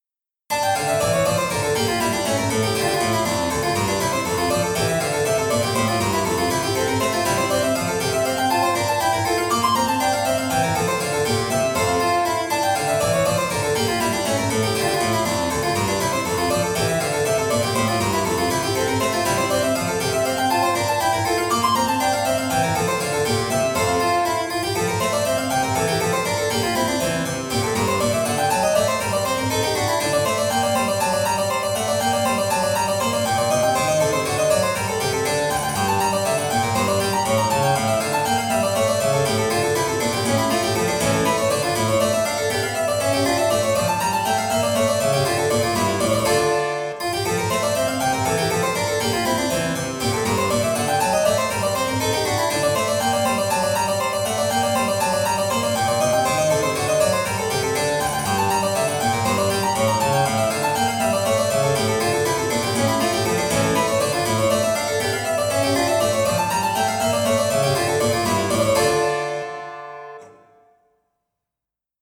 Посоветуйте хороший классический клавесин